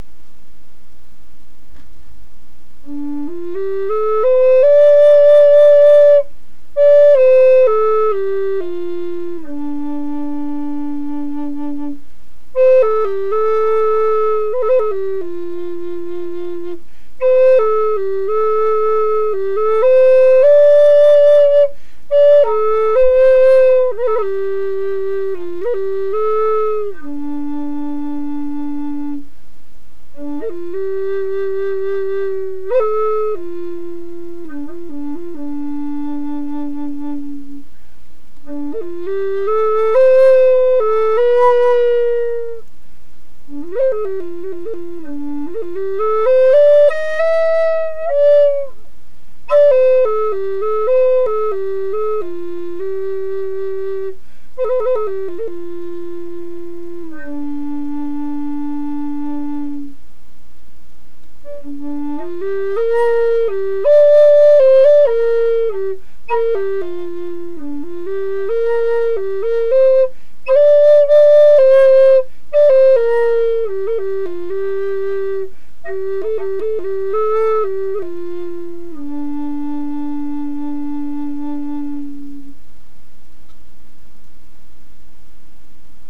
Plains-style Native American flute in the key of D
Finger spacing:   Low DÂ minor
ButternutÂ